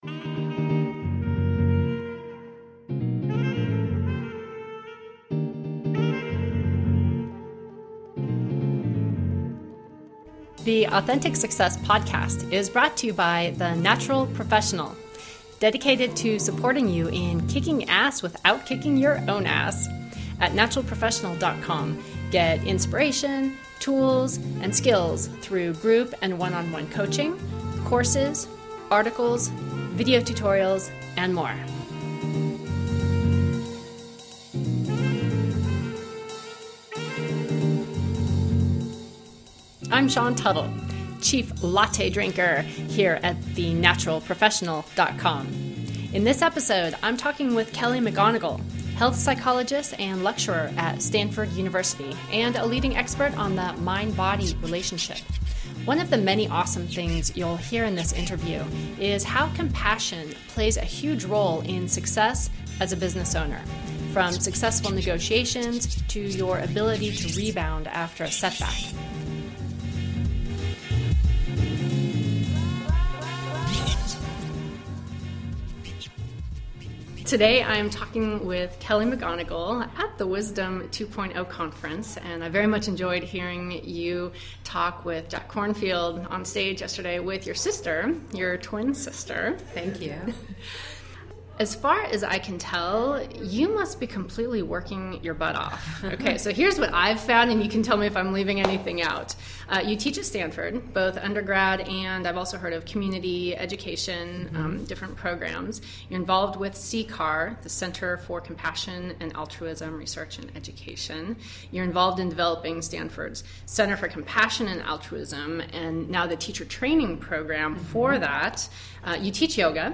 Interview with Kelly McGonigal, Ph.D.
I had the honor of talking with her at the Wisdom 2.0 Conference and greatly enjoyed her approach, which is passionate, insightful and relevant to daily life—all at the same time.